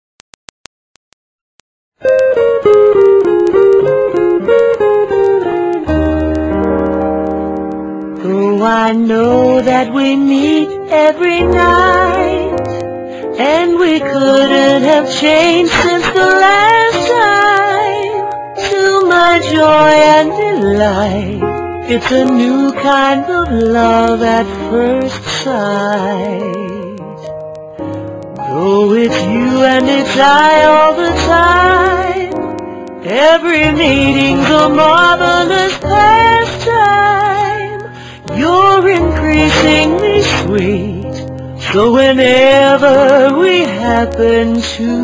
NOTE: Vocal Tracks 1 Thru 11